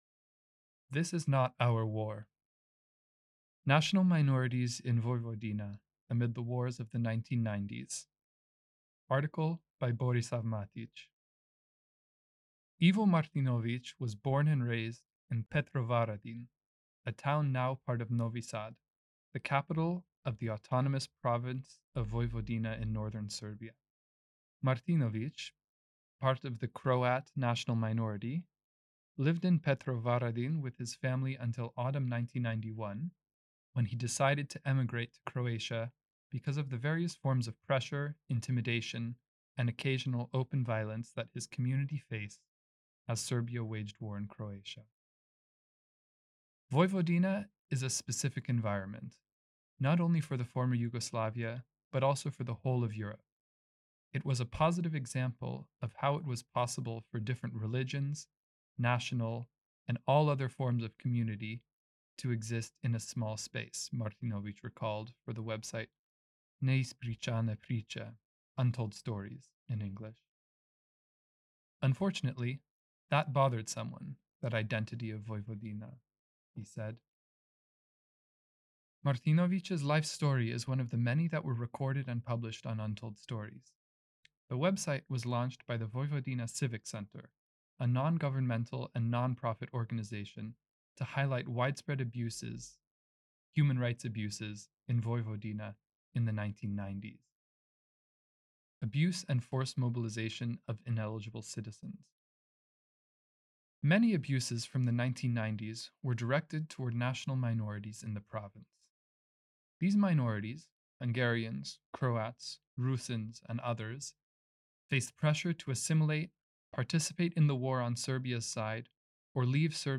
Below is a read-aloud version of the entire article.